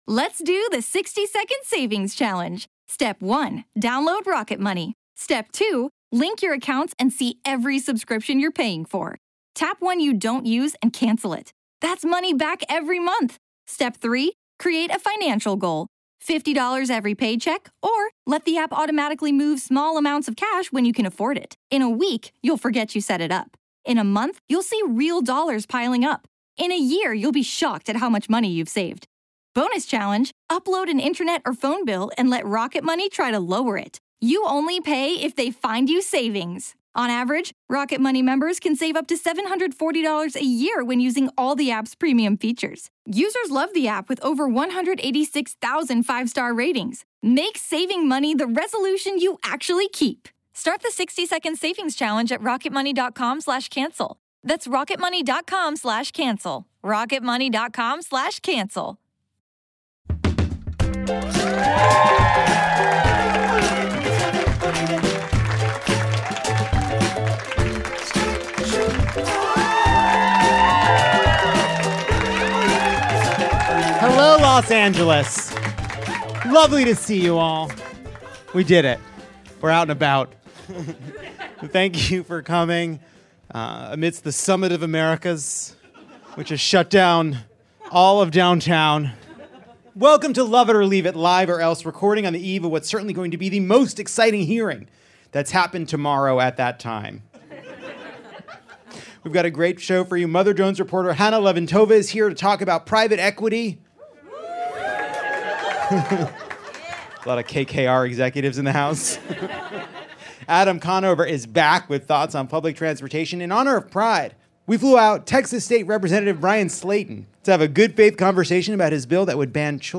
Lovett or Leave It takes summer by storm with the help of the good people at Los Angeles’s fabulous Dynasty Typewriter.